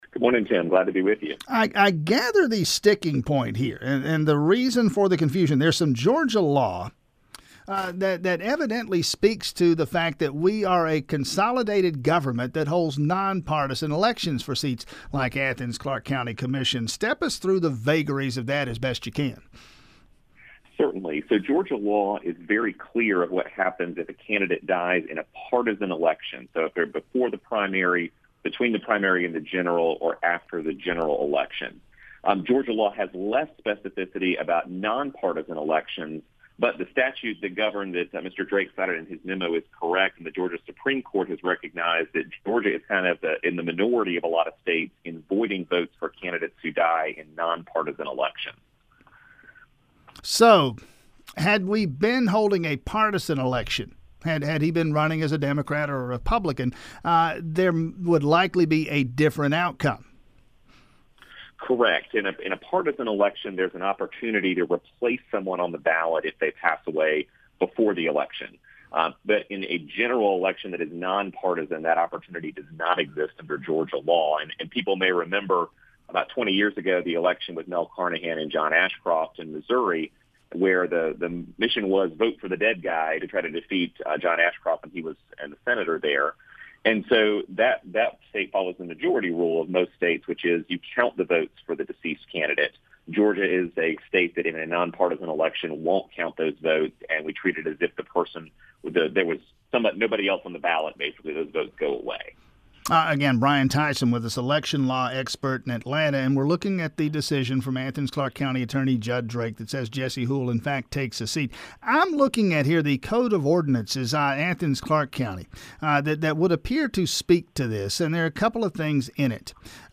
INTERVIEW (Audio)